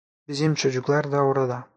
/tʃo.dʒukˈlaɾ/